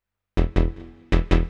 hous-tec / 160bpm / bass